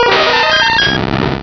pokeemerald / sound / direct_sound_samples / cries / mew.aif
-Replaced the Gen. 1 to 3 cries with BW2 rips.
mew.aif